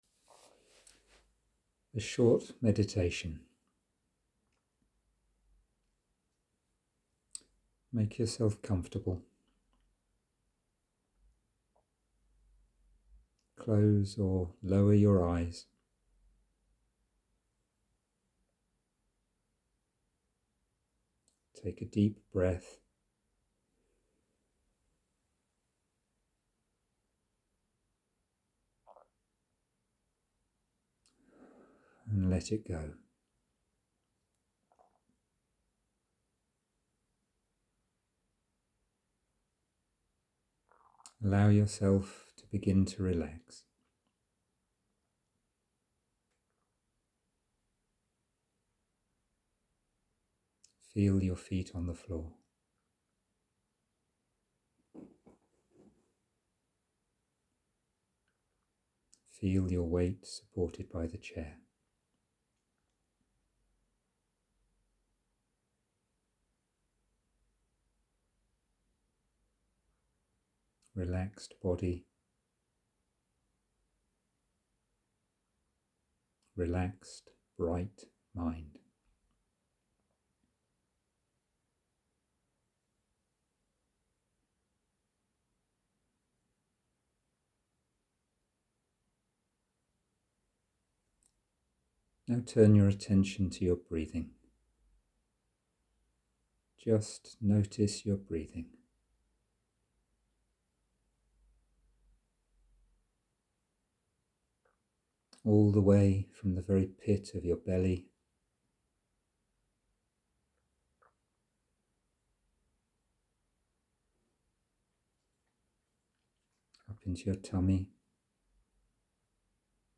Breathing Meditation
Breathing Meditation.m4a